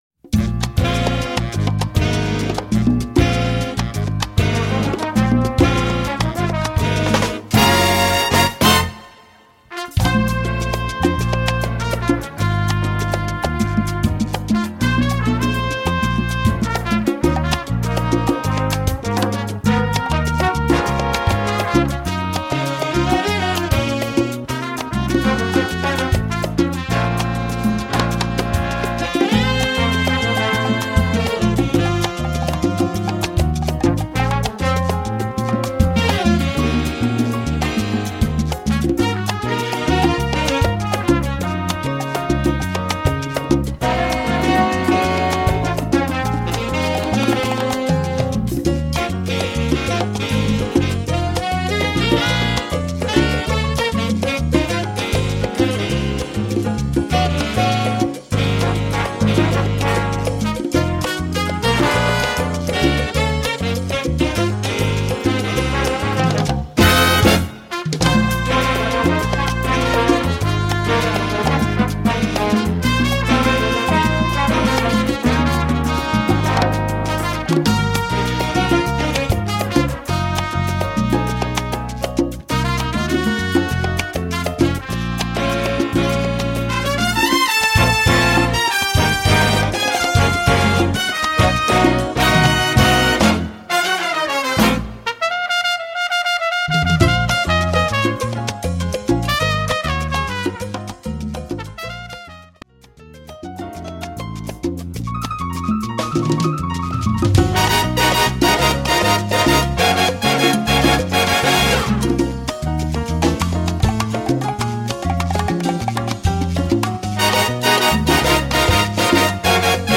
Category: big band
Style: mambo
Solos: trumpet 1-2, tenor 2
Instrumentation: big band (4-4-5, rhythm (4)